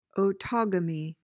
PRONUNCIATION:
(o-TOG-uh-mee)